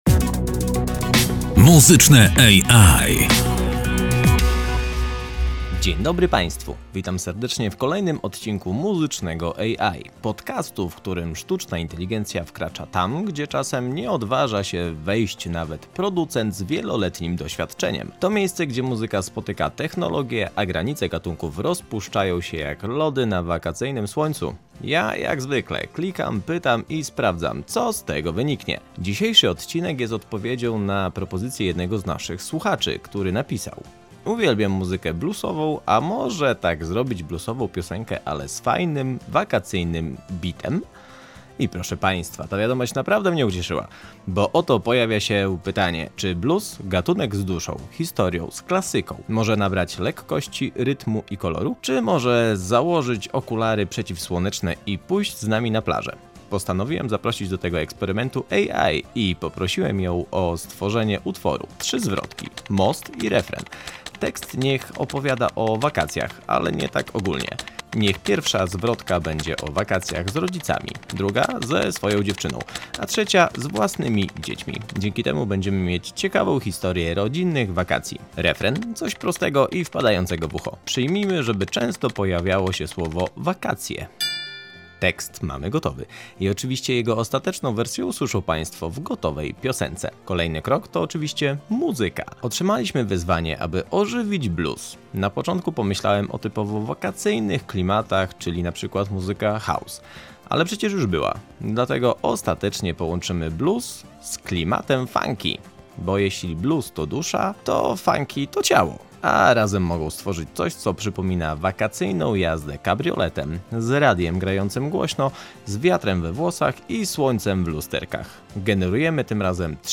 Sztuczna inteligencja dostała konkretne zadanie: napisać tekst o wakacjach z rodzicami, z ukochaną i z własnymi dziećmi. Refren – prosty, chwytliwy i pełen słońca, z powracającym motywem słowa „wakacje”.
Muzycznie postawiliśmy na połączenie bluesowej duszy z funkowym ciałem. Spośród trzech wersji wygenerowanych przez AI wybraliśmy tę, która najtrafniej uchwyciła wakacyjny klimat: klasyczne riffy, pulsujący rytm, dęciaki pełne koloru i groove, który sprawia, że chce się jechać kabrioletem w stronę słońca.